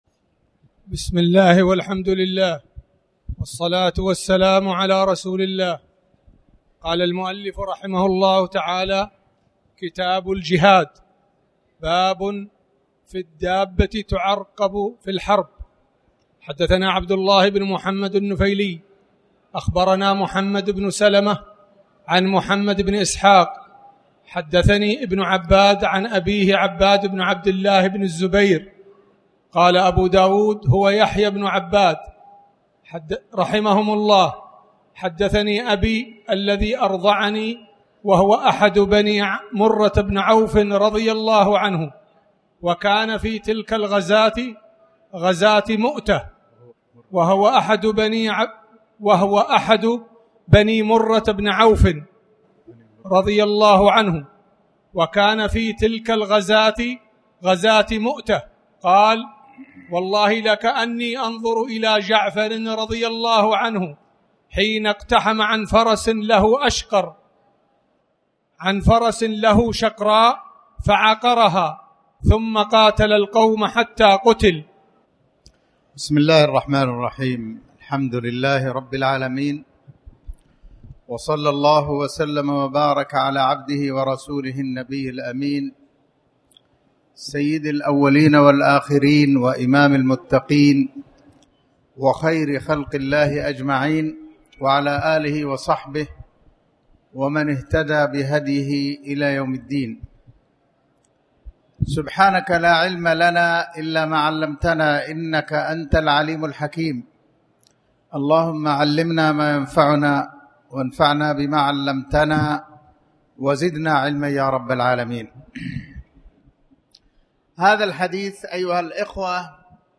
تاريخ النشر ١٤ محرم ١٤٣٩ هـ المكان: المسجد الحرام الشيخ